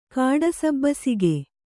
♪ kāḍasabbasige